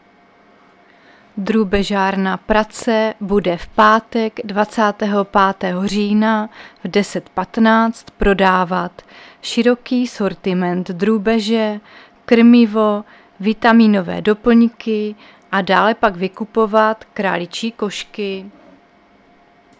Záznam hlášení místního rozhlasu 23.10.2024
Zařazení: Rozhlas